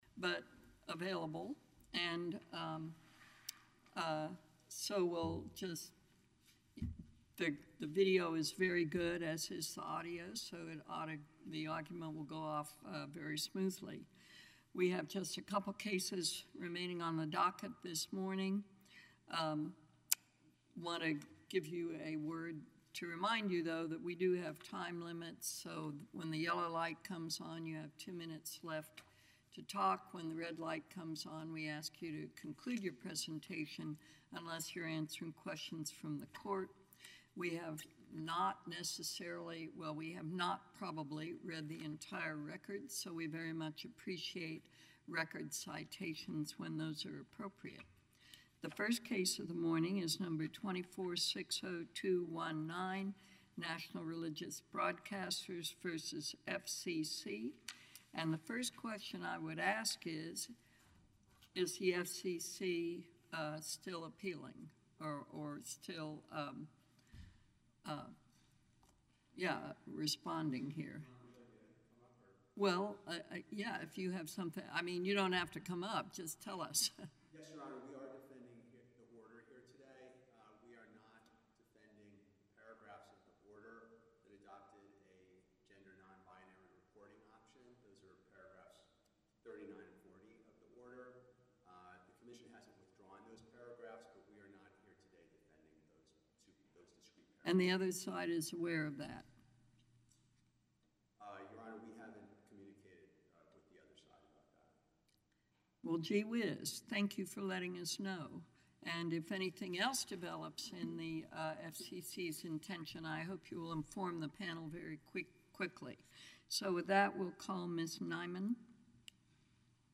The U.S. Court of Appeals for the Fifth Circuit heard oral argument on the legal challenge to the FCC’s reinstatement of the FCC Form 395-B (a recording of the oral argument can be found here ).